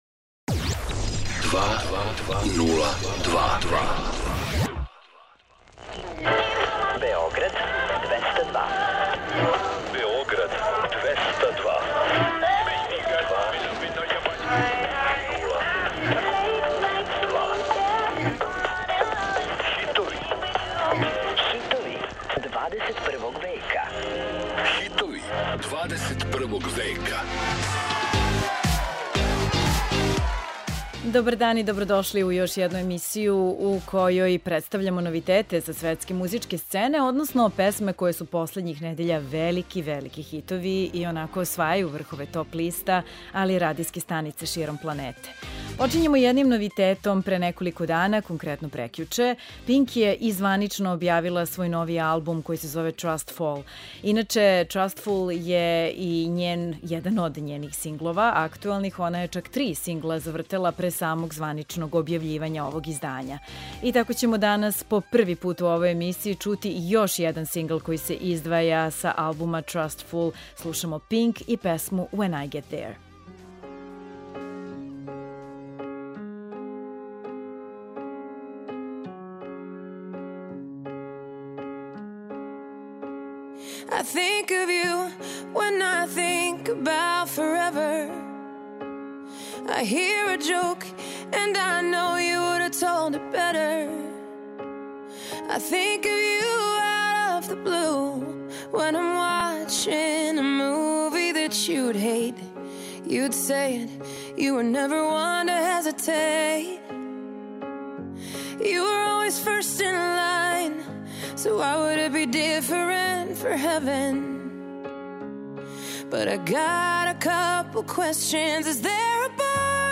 Čućete pesme koje se nalaze na vrhovima svetskih top lista.